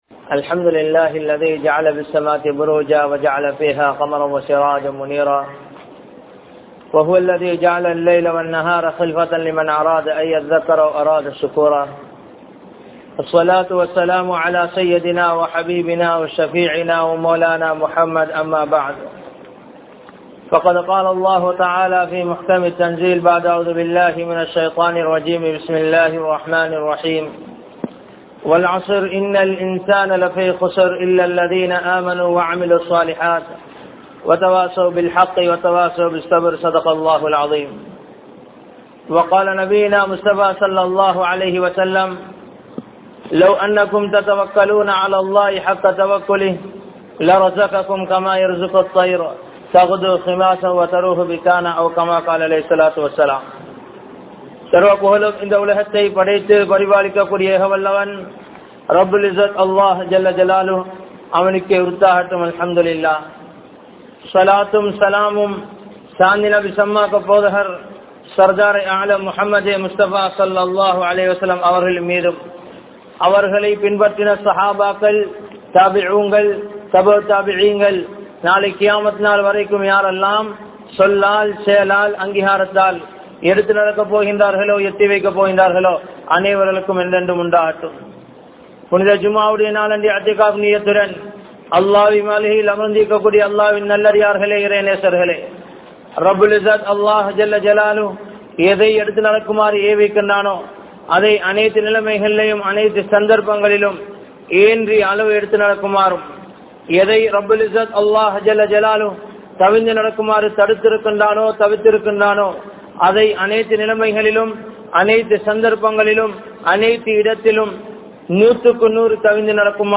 Vaalkaiyai Amalaaha Maattruvoam (வாழ்க்கையை அமலாக மாற்றுவோம்) | Audio Bayans | All Ceylon Muslim Youth Community | Addalaichenai
Mawanella, Danagama, Masjidhul Hakam Jumua Masjidh